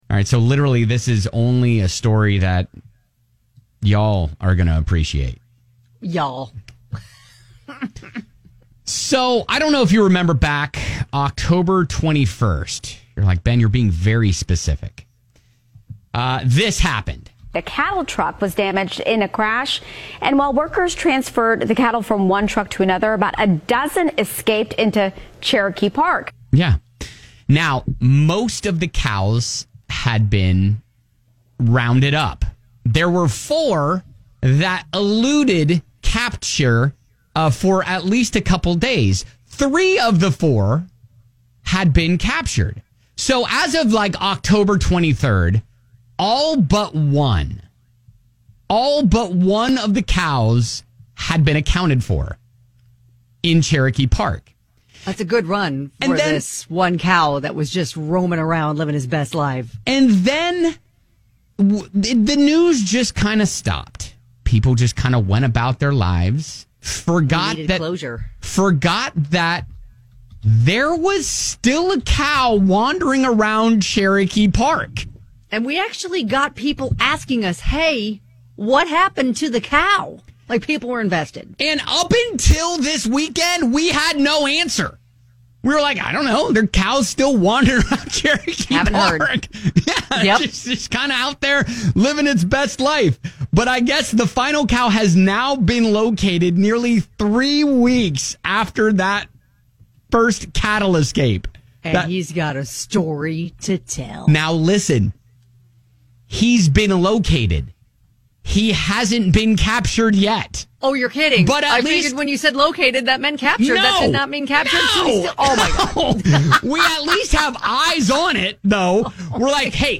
ONLY in Louisville will you get an update on morning radio on the missing cow in Cherokee Park...